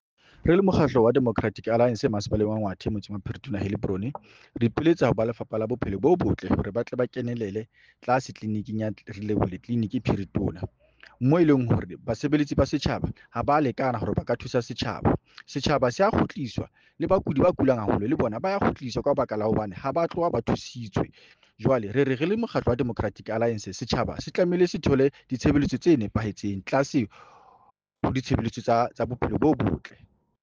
Sesotho soundbite by Cllr Joseph Mbele.